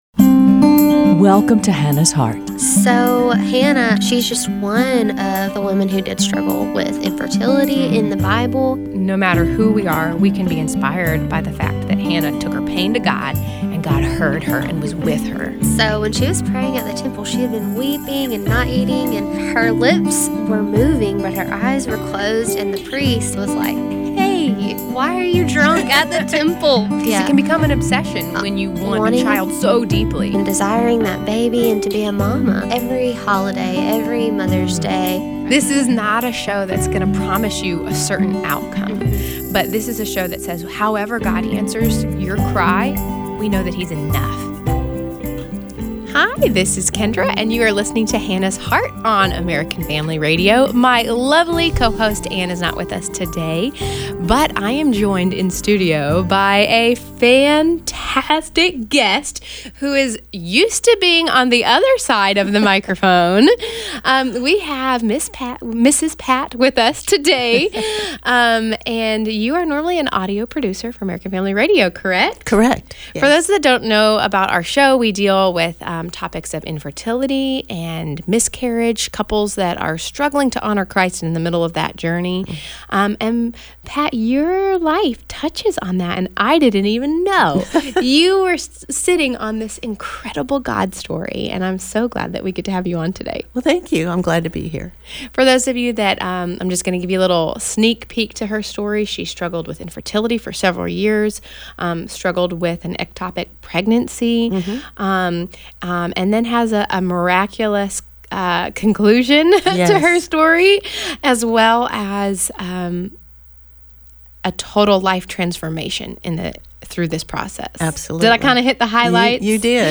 This is the testimony of a woman who was far from God and unable to have a baby.